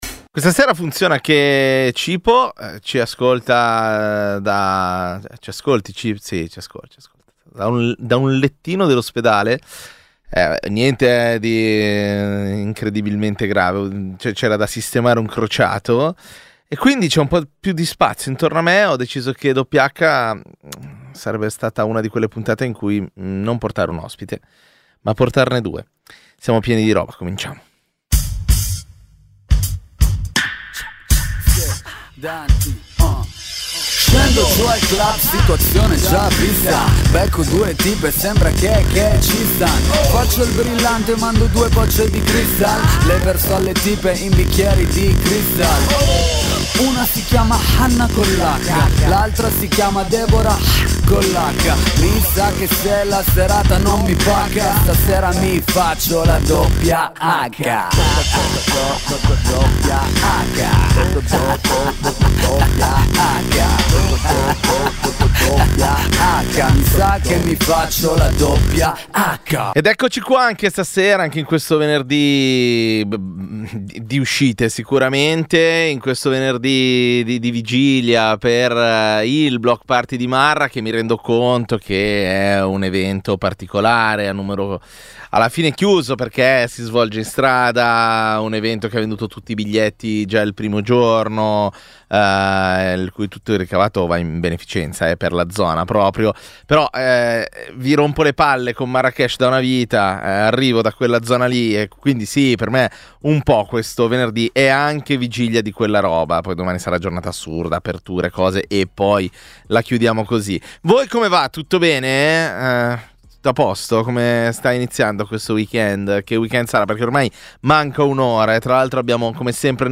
Dal 2011 è la trasmissione dedicata all’hip-hop di Radio Popolare.